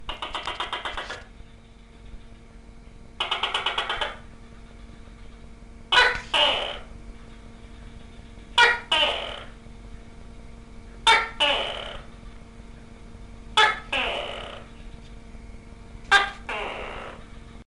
Здесь вы найдете как тихие щелчки, так и выразительные трели этих удивительных рептилий.
Такие звуки издает геккон